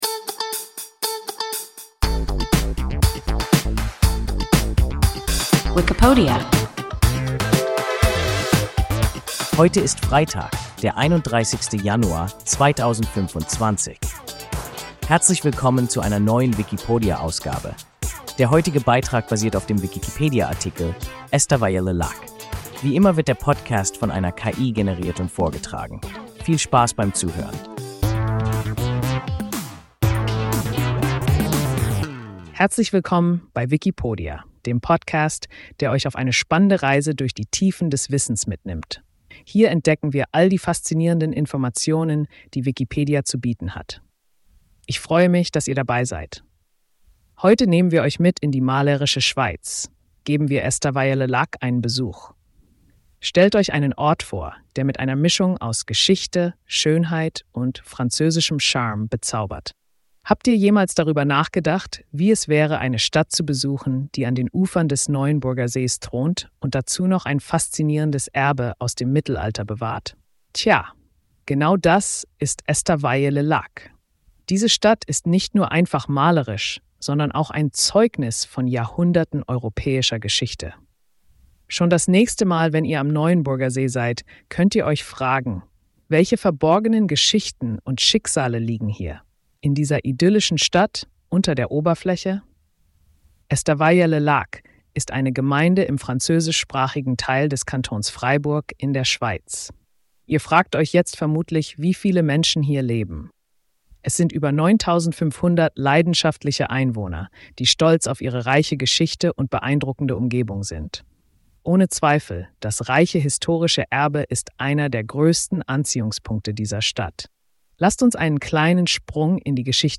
Estavayer-le-Lac – WIKIPODIA – ein KI Podcast